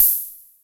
Closed Hats
Wu-RZA-Hat 65.wav